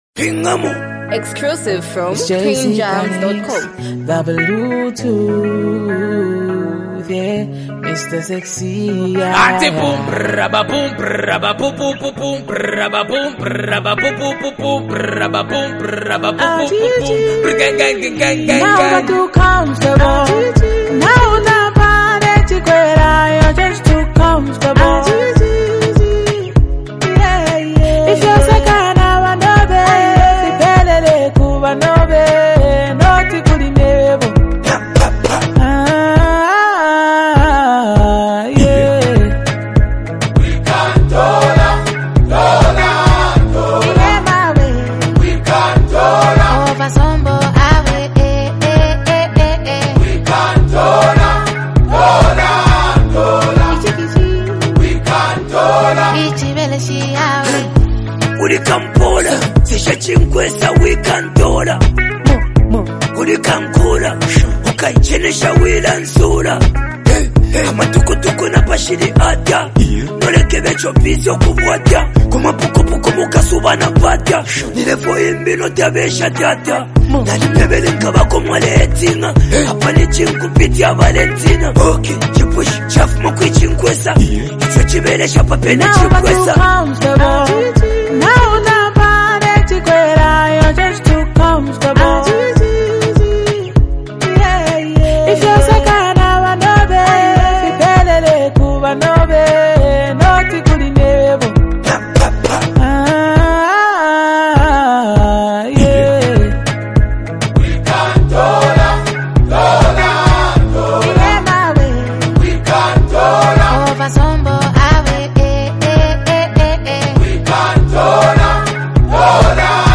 rapper duo